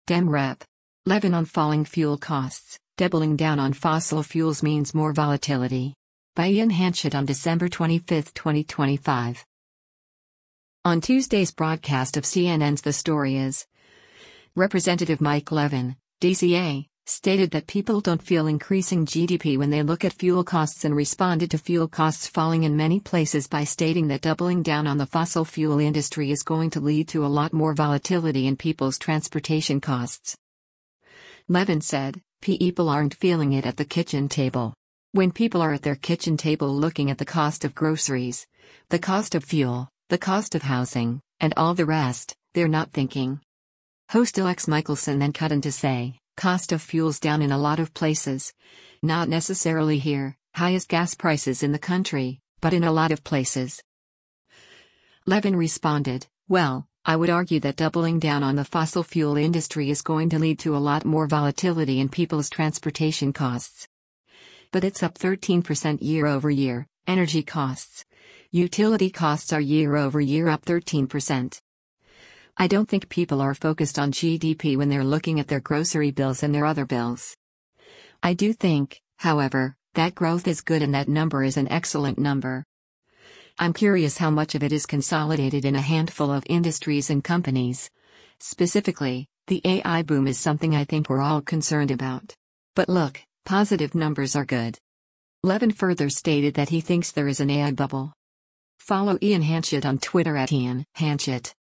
On Tuesday’s broadcast of CNN’s “The Story Is,” Rep. Mike Levin (D-CA) stated that people don’t feel increasing GDP when they look at fuel costs and responded to fuel costs falling in many places by stating that “doubling down on the fossil fuel industry is going to lead to a lot more volatility in people’s transportation costs.”